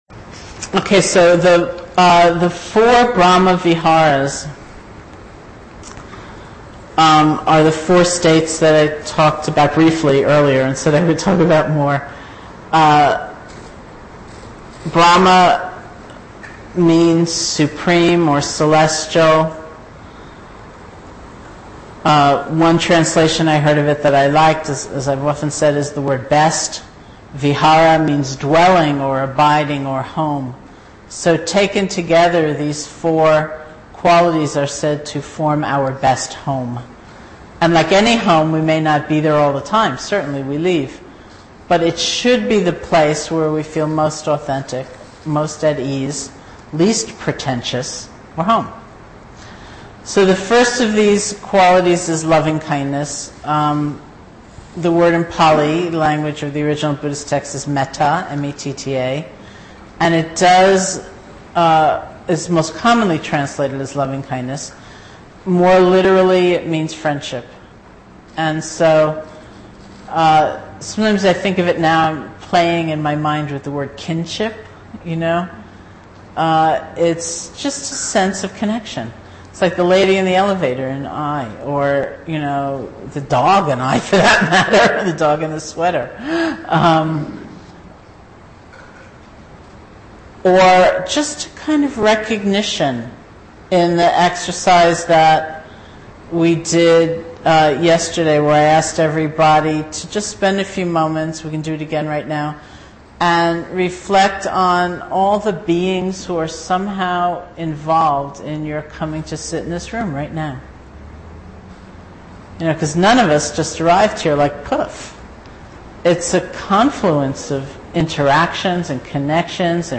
Teacher: Sharon Salzberg Date: 2009-07-25 Venue: Seattle Insight Meditation Center Series [display-posts] Description The four Brahma Viharas.